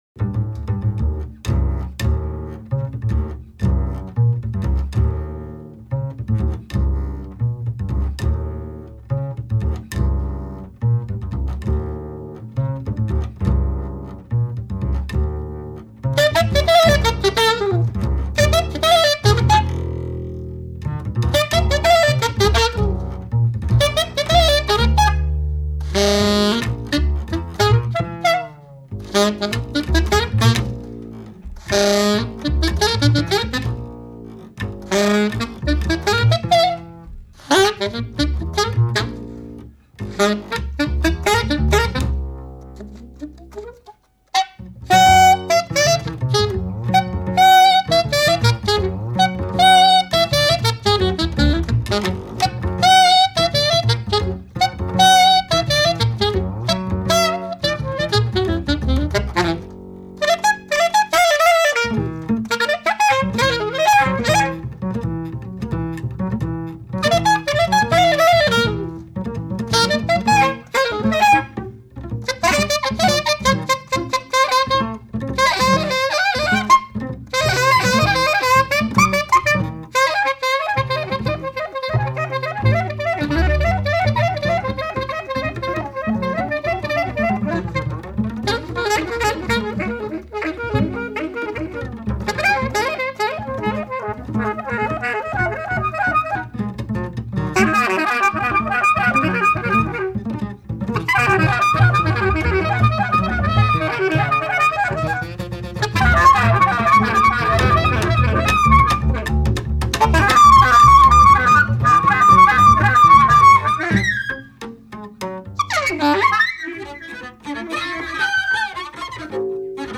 En voici un exemple vivant, vivifiant, expressif, sauvage.
Enregistré à la Bibliothèque de Massy, 13 novembre 1980.